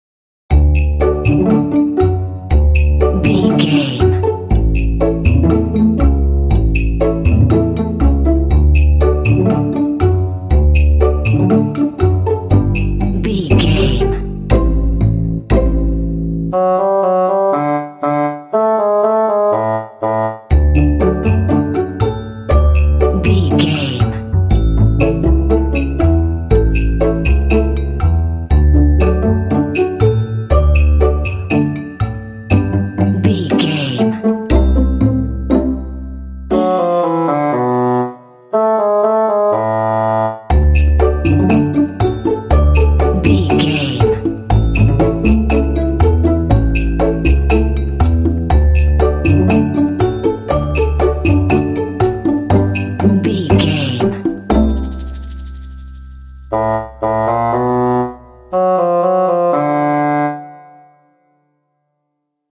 Uplifting
Ionian/Major
D
happy
bright
funny
joyful
double bass
strings
conga
percussion
bassoon
contemporary underscore